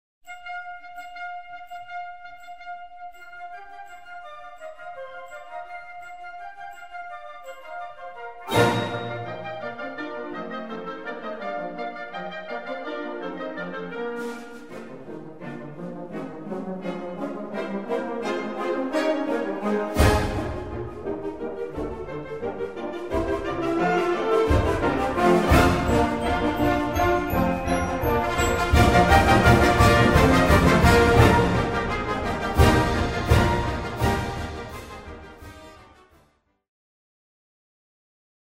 Gattung: Russische Tanzsuite
Besetzung: Blasorchester